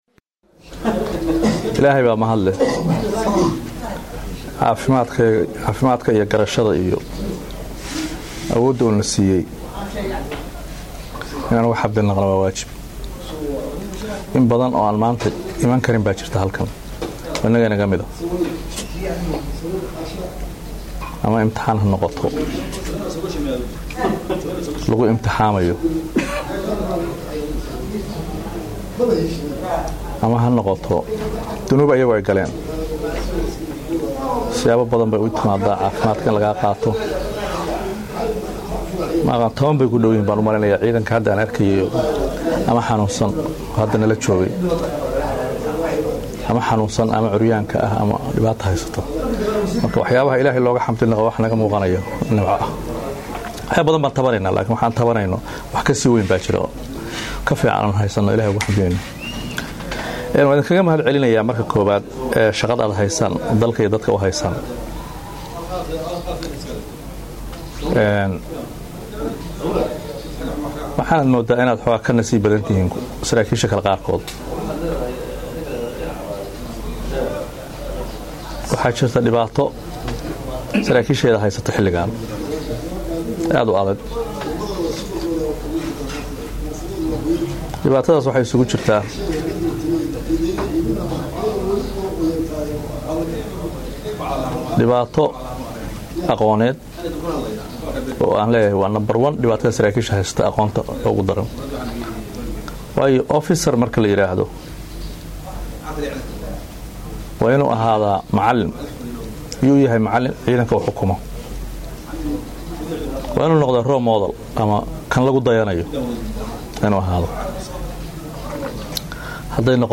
Dhagayso Taliyaha Ciidamada Booliska Puntland Jen. C/qaadir Shire Faarax Erag